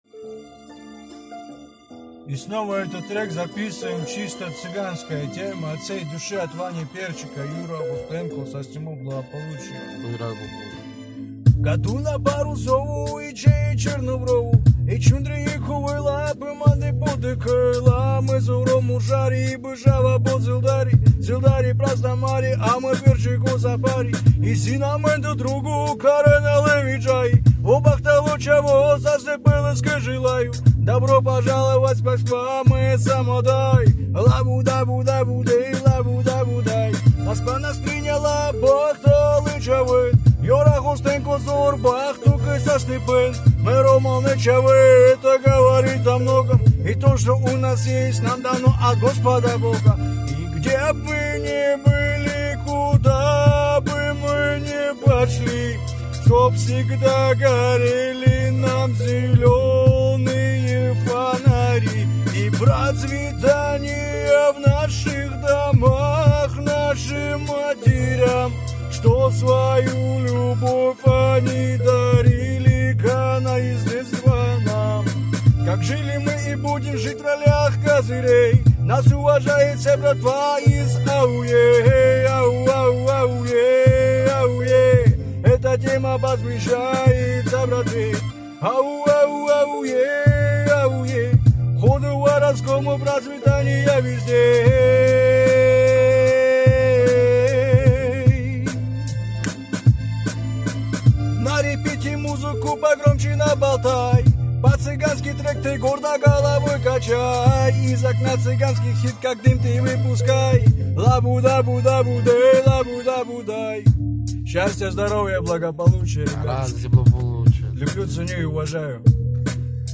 Цыганская песня
originall bass eidition